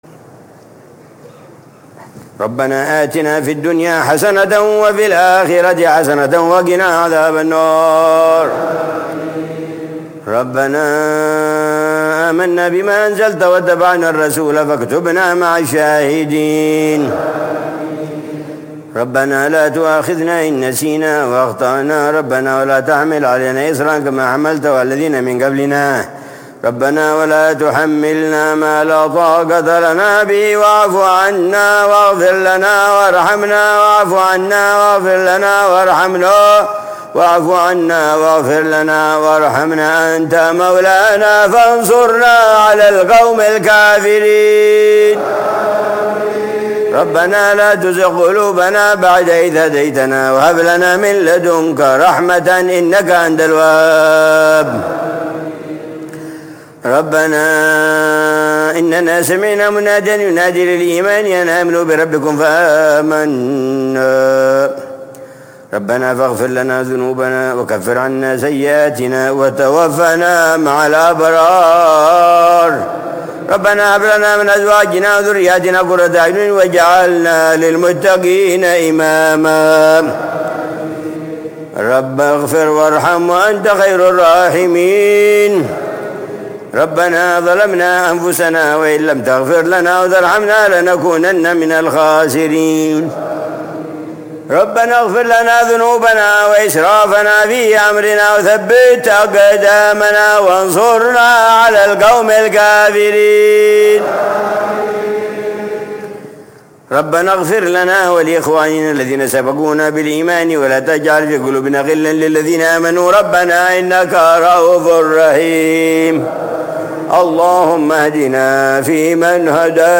دعاء العلامة الحبيب عمر بن حفيظ في قنوت الوتر، ليلة الأربعاء 19 رمضان 1446هـ ( يوم ينفع الصادقين صدقهم )